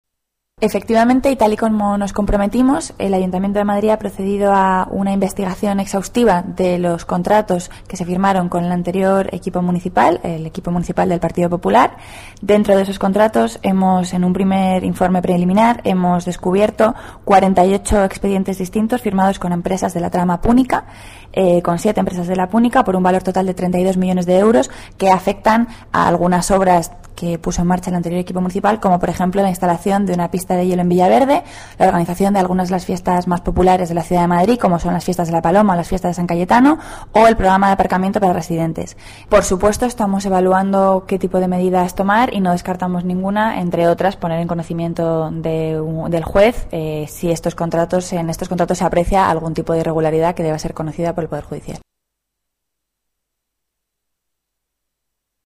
Nueva ventana:Declaraciones de Rita Maestre sobre el caso Púnica